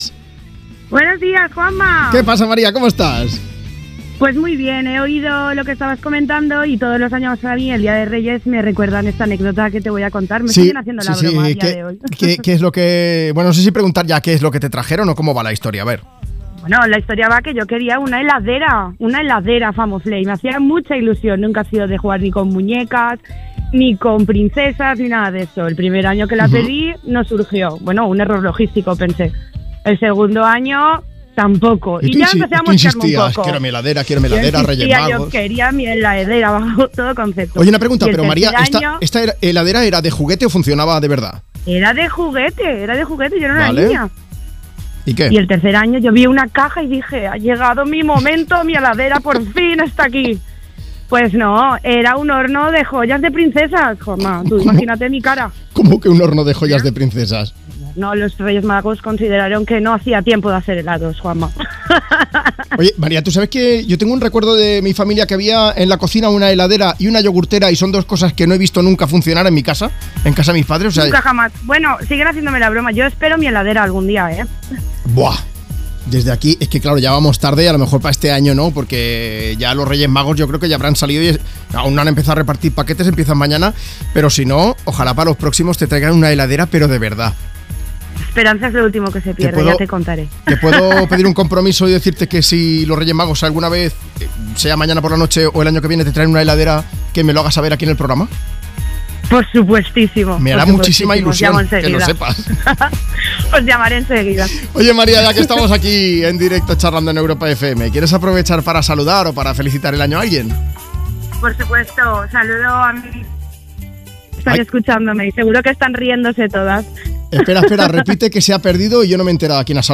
una oyente de Me Pones, cuenta en directo que los Reyes Magos nunca le regalaron una heladera de juguete.